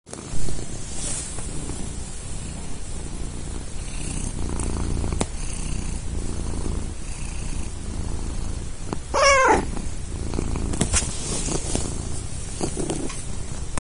Ronroneo de gato con lluvia de fondo ALAJUELA